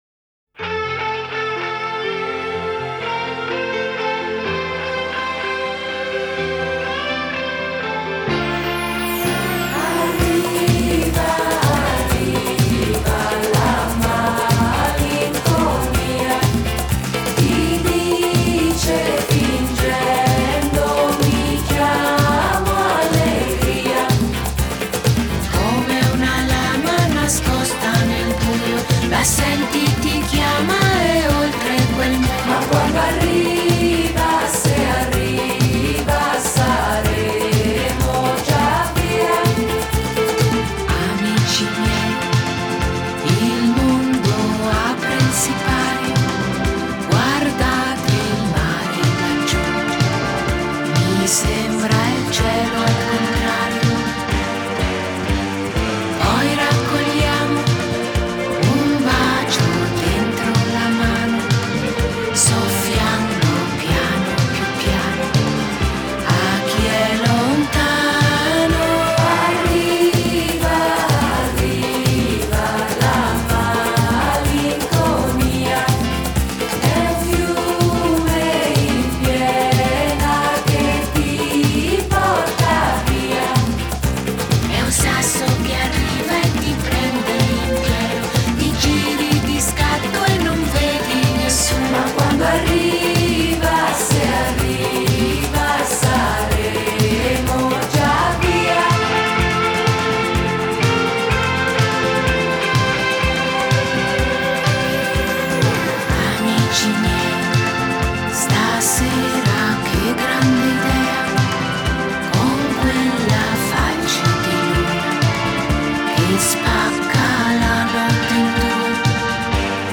Genre: Pop, Disco,Nu-Disco,Dance